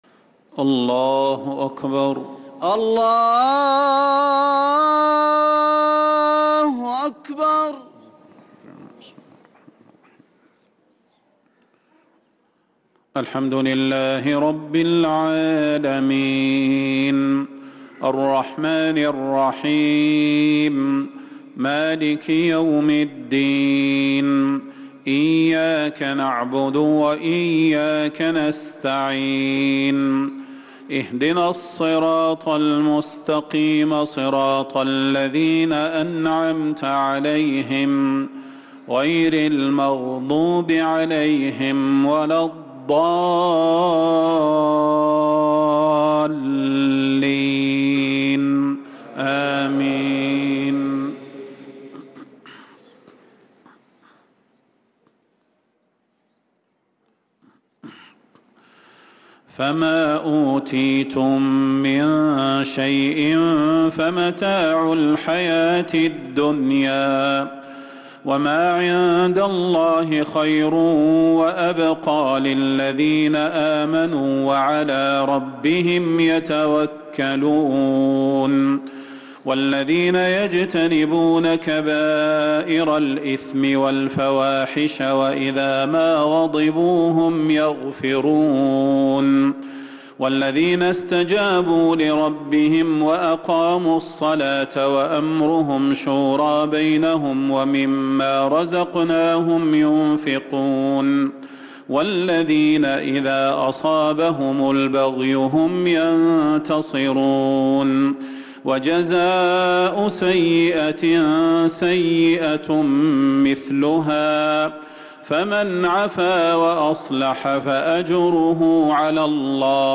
صلاة العشاء للقارئ صلاح البدير 12 رجب 1445 هـ
تِلَاوَات الْحَرَمَيْن .